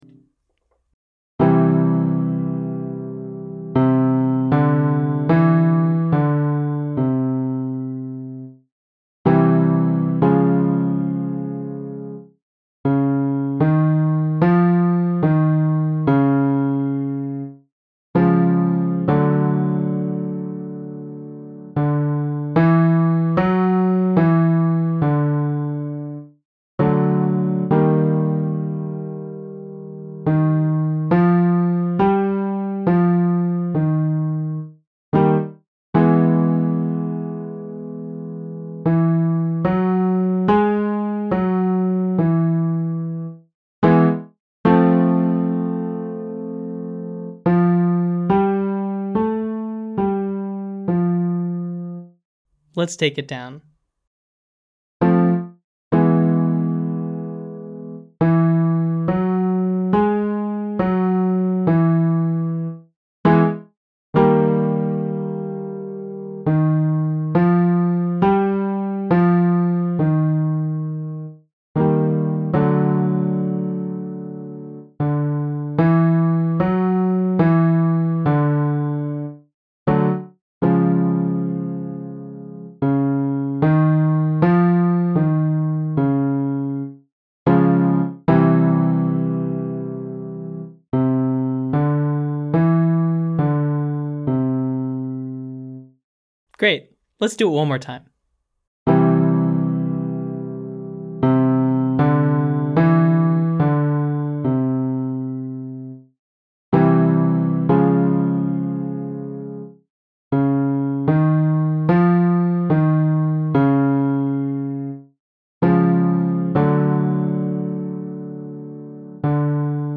Matching An Instrument - Online Singing Lesson